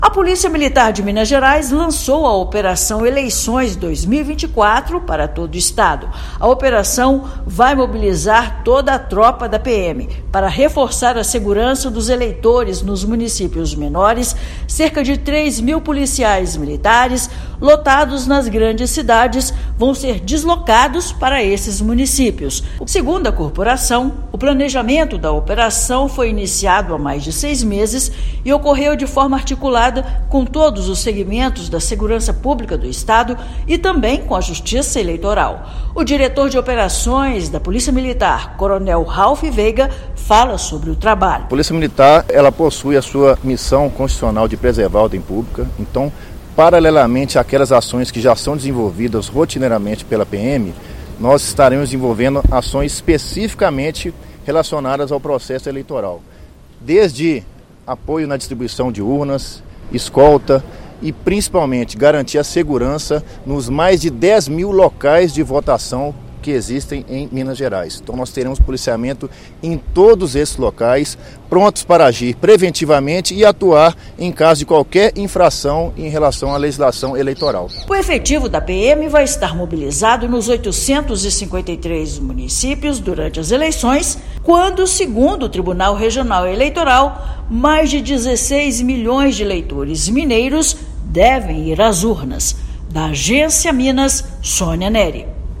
Planejamento de segurança da instituição para apoio ao TRE-MG contará com emprego de toda a tropa nos 853 municípios. Ouça matéria de rádio.